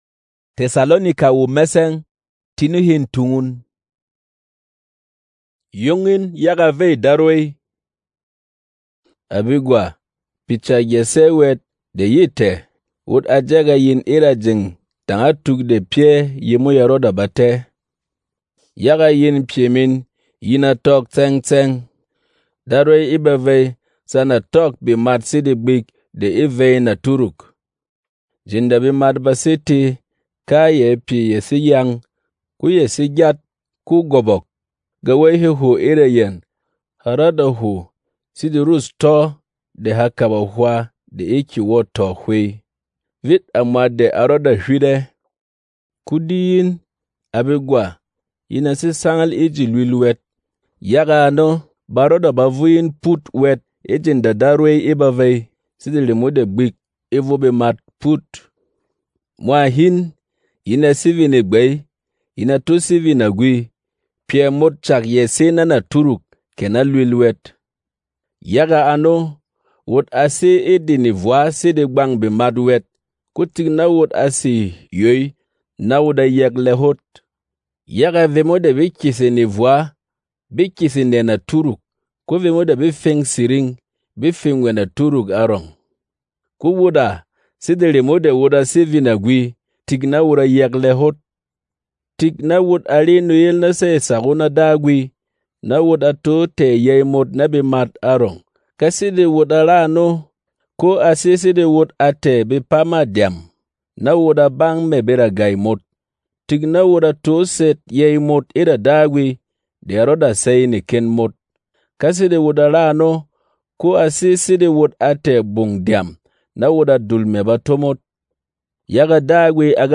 Berom MP3 Bible ⚙
Non-Drama